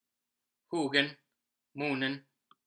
Huginn and muninn pronunciation
huginn-and-muninn-pronunciation.m4a